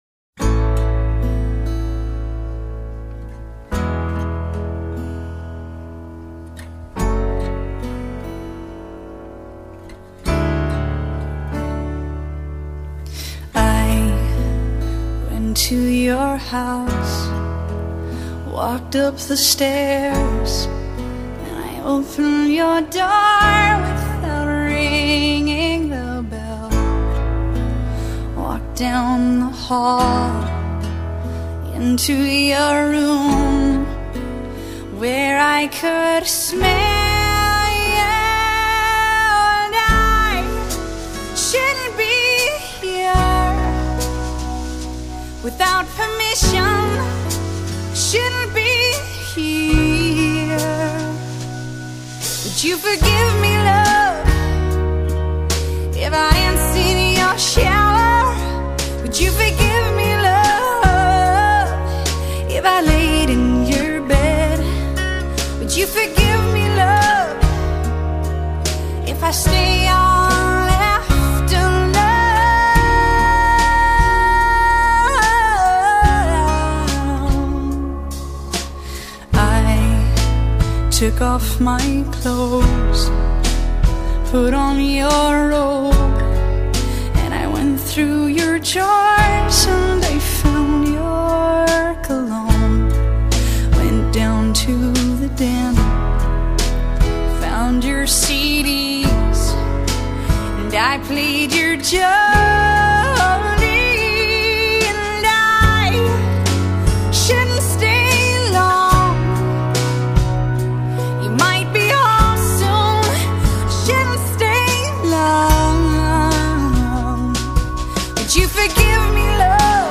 音樂類型：西洋音樂